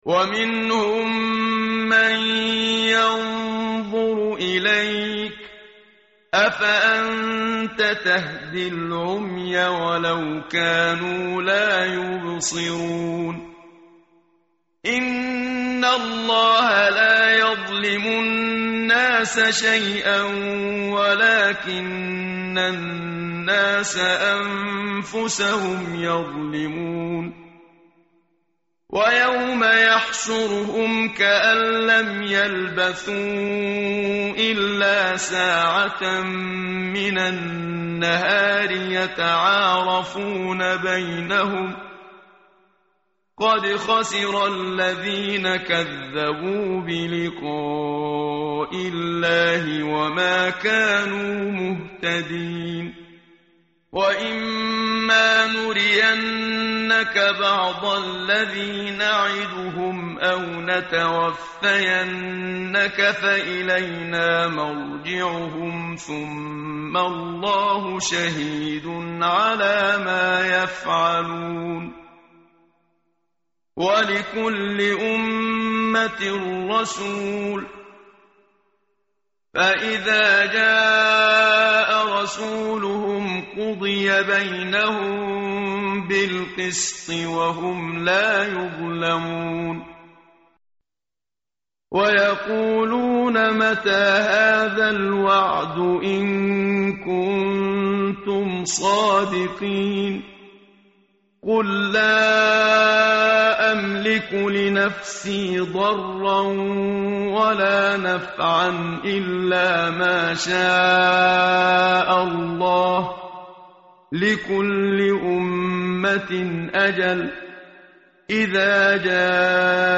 tartil_menshavi_page_214.mp3